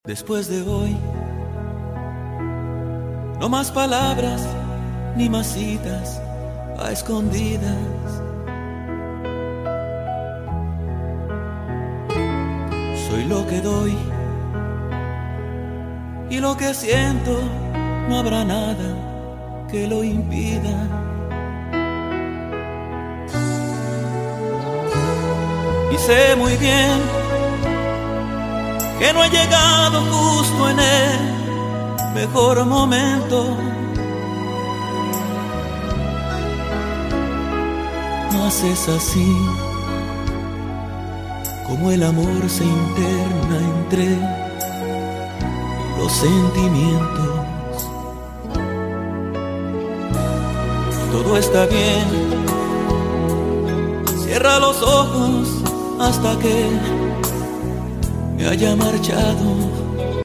la última parte en vivo en Puerto Rico